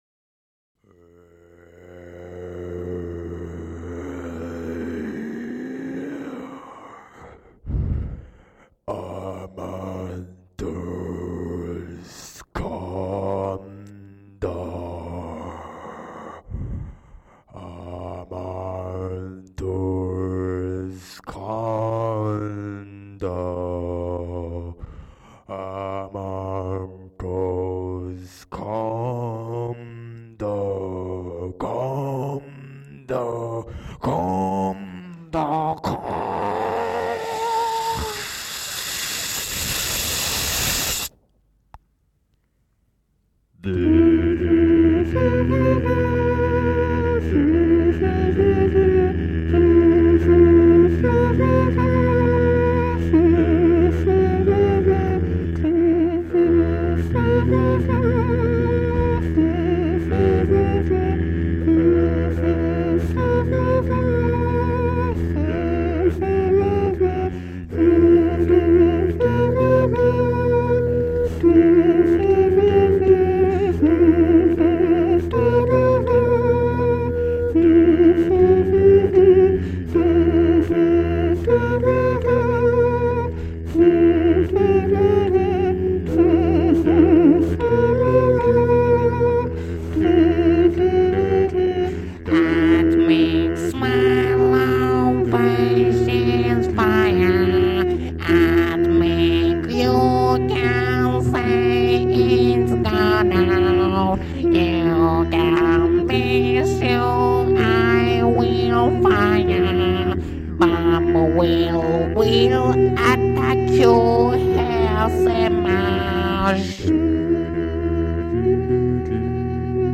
Metal classics done a capella by a Japanese guy.
All vocals and music done entirely by voice...
cover